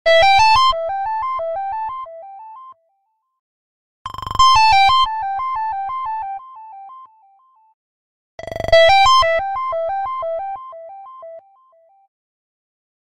Kategori Telefon